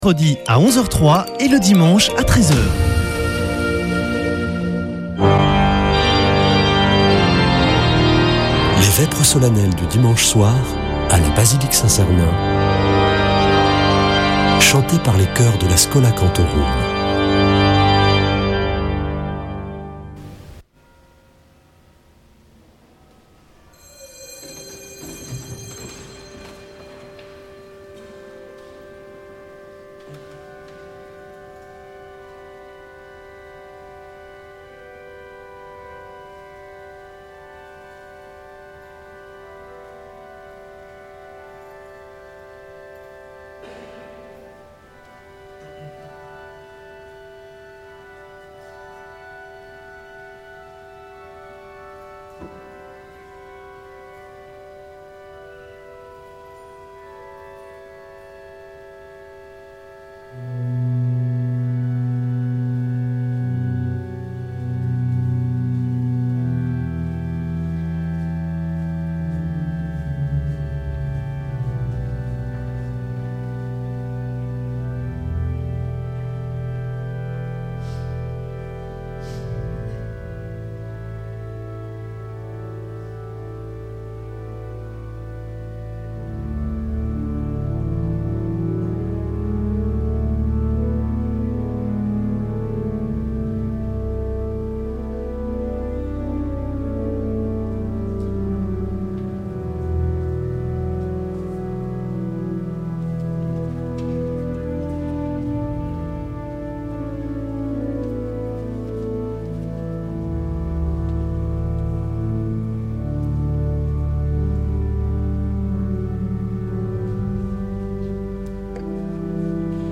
Vêpres de Saint Sernin du 15 juin